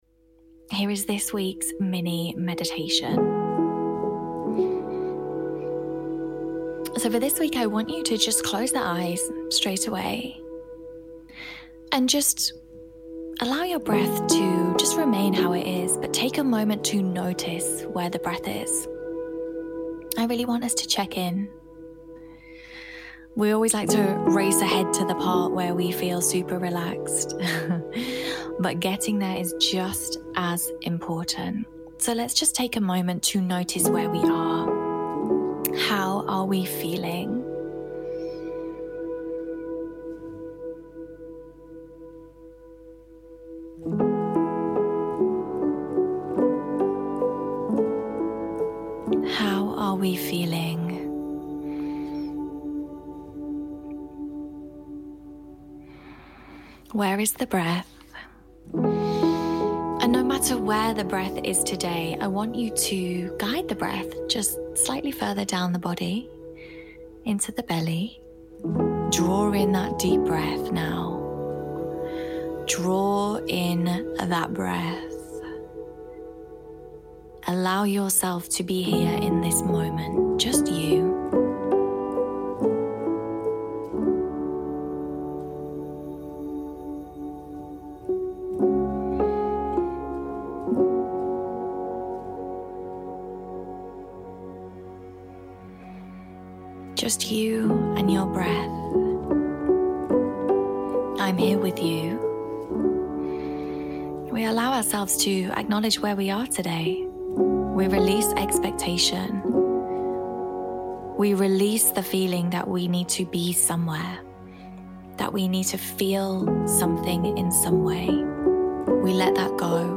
Mini Meditation for why we are here